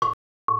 The first 120 milliseconds of the A2 bar is heard first, then the synthetic sound.